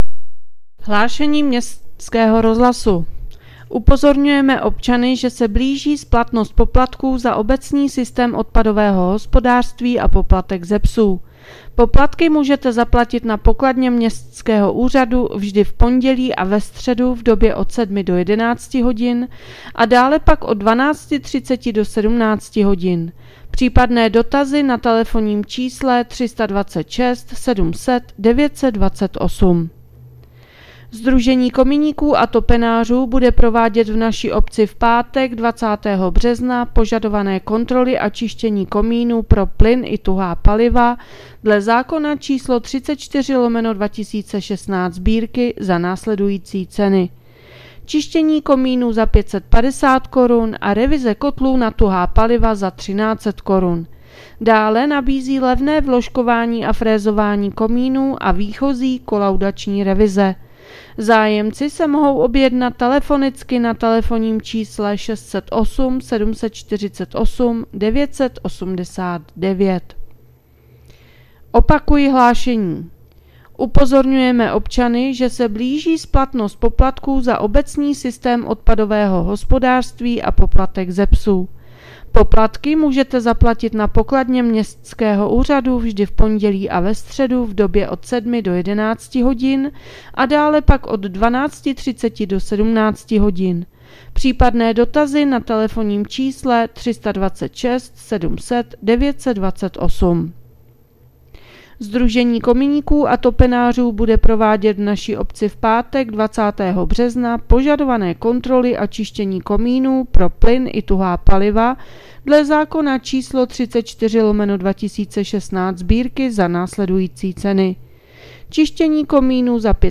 Hlášení městského úřadu 16.3.2026